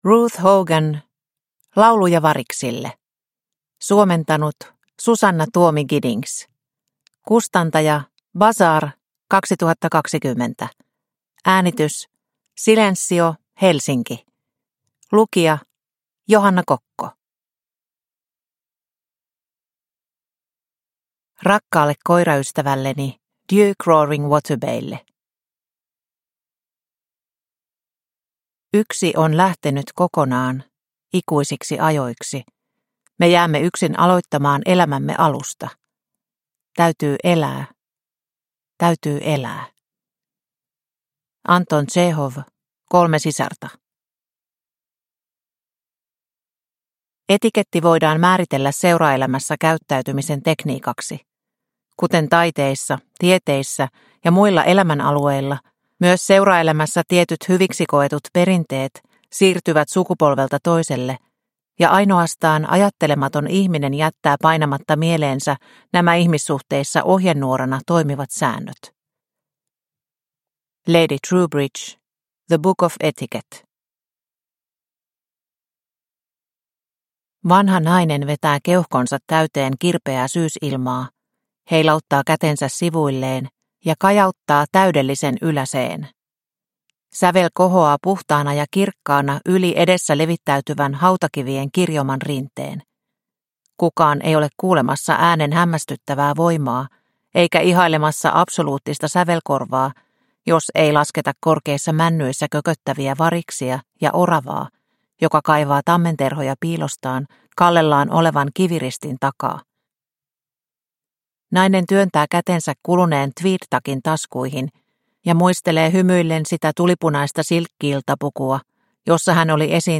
Lauluja variksille – Ljudbok – Laddas ner